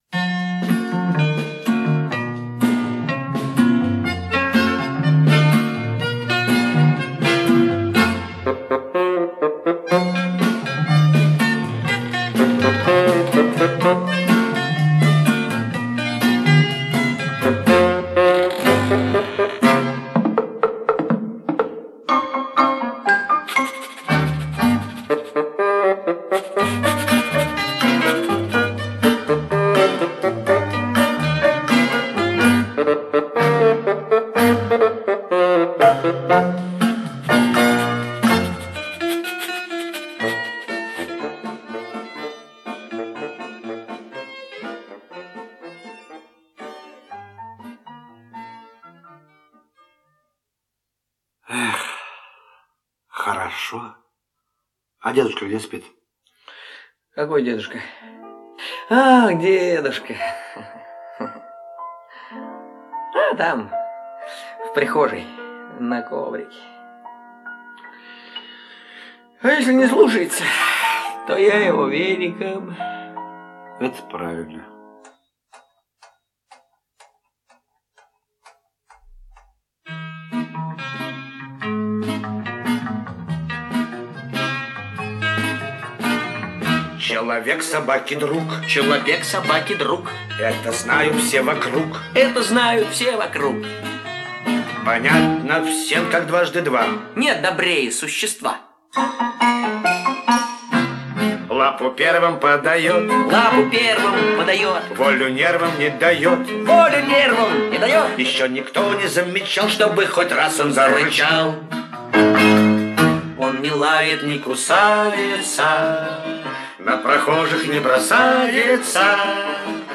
это детская песенка-перевертыш